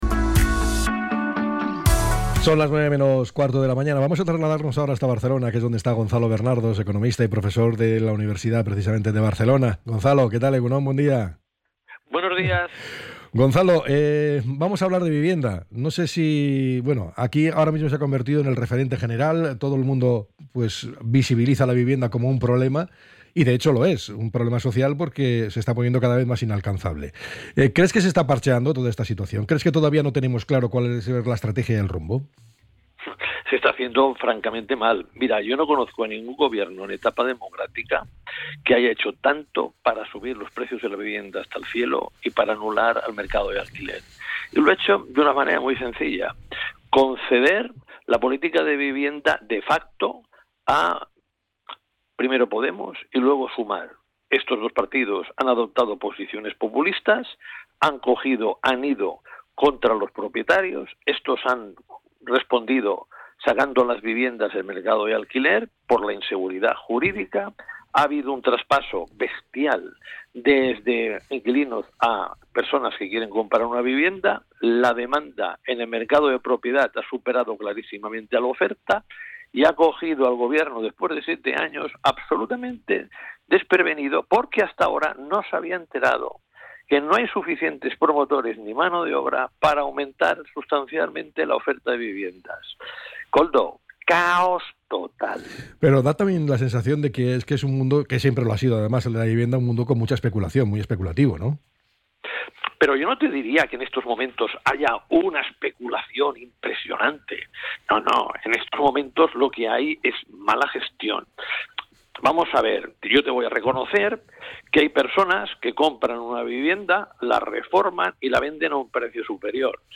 ENTREV.-BERNARDOS-05-02.mp3